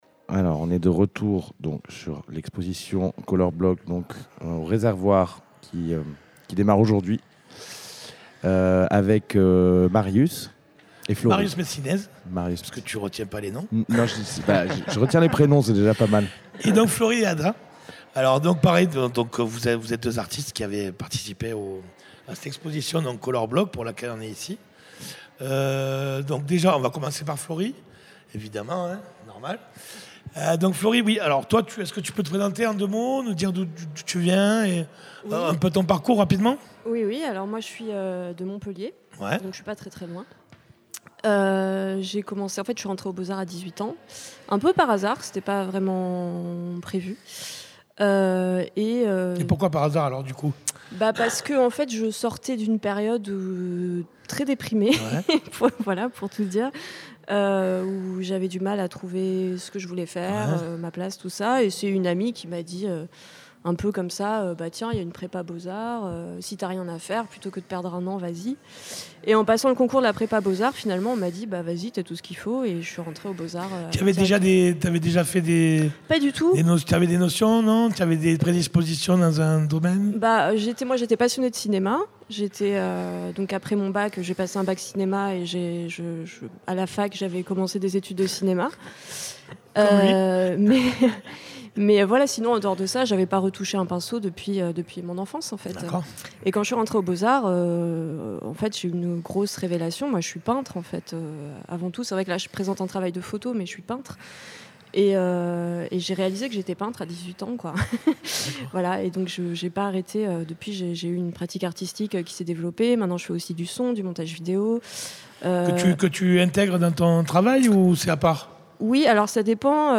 ITW